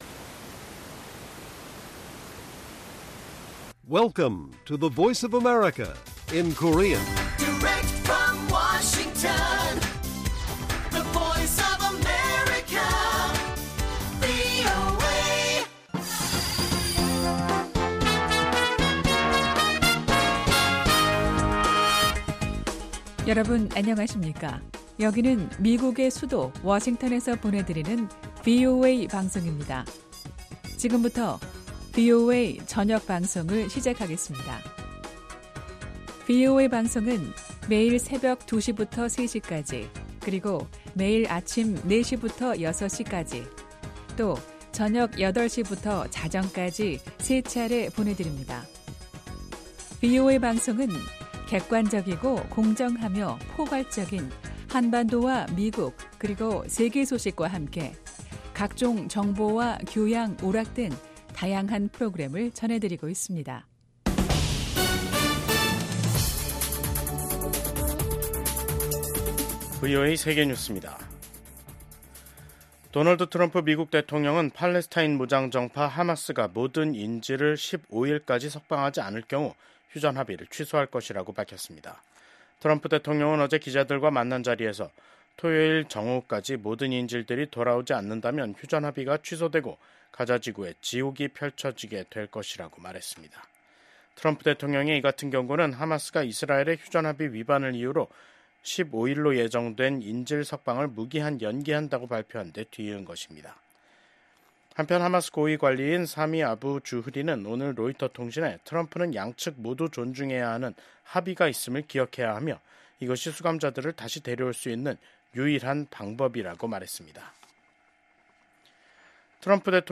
VOA 한국어 간판 뉴스 프로그램 '뉴스 투데이', 2025년 2월 11일 1부 방송입니다. 도널드 트럼프 미국 행정부 출범 이후 대미 담화 빈도를 크게 늘린 북한이 이번엔 미국의 원자력 추진 잠수함(SSN)의 부산 입항을 비난하는 담화를 냈습니다. 미국의 한반도 전문가들은 지난주 미일 정상회담이 북한 비핵화와 미한일 3국 공조를 강조한 것에 주목하며 한반도와 역내 평화와 안보에 기여할 것으로 기대했습니다.